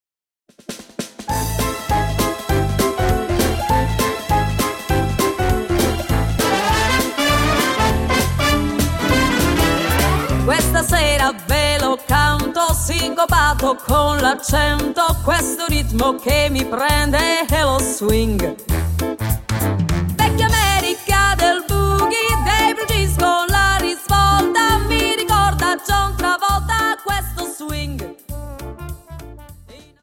Quickstep 50 Song